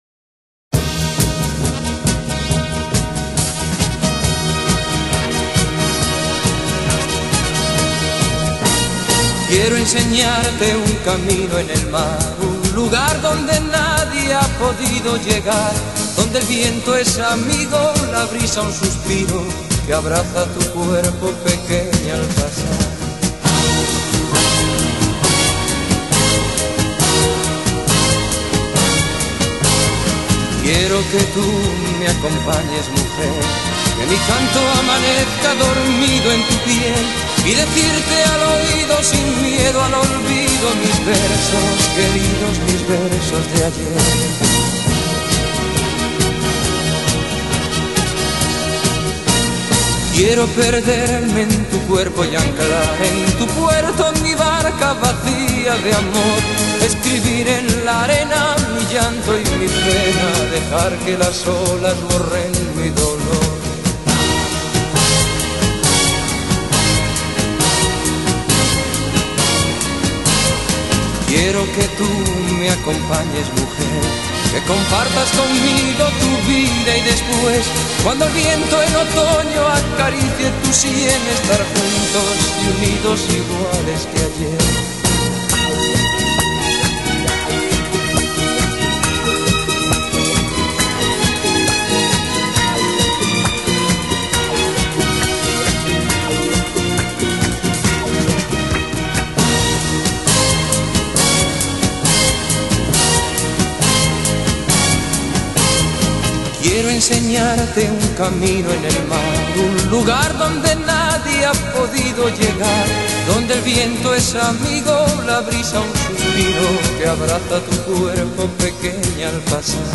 Genre: Latin, folk, vocal